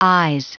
Prononciation du mot is en anglais (fichier audio)
Prononciation du mot : is